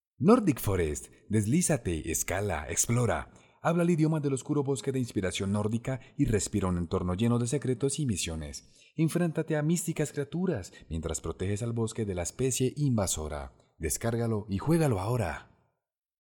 Ingeniero de profesion, capacitado en doblaje y locucion, participacion en fandubs, voz grave natural, con matices e interpretacion para alcanzar varios tonos.
kolumbianisch
Sprechprobe: Werbung (Muttersprache):